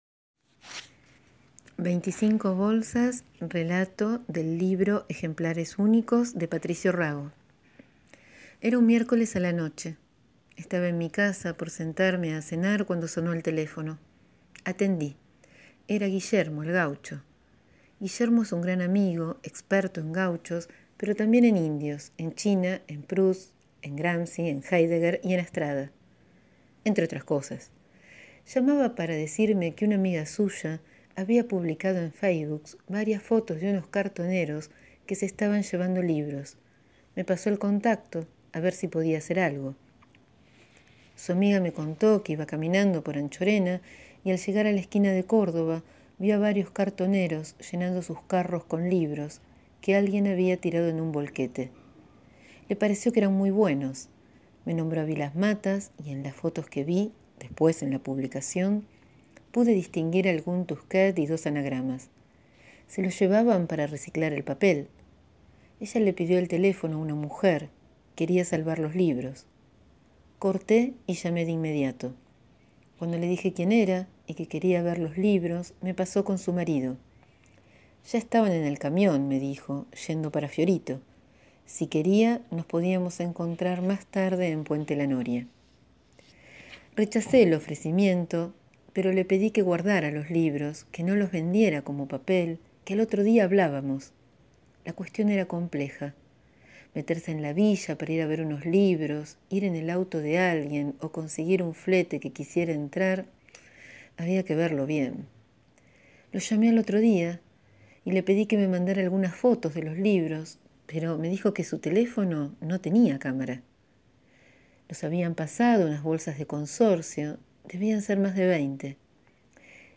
Hoy les leo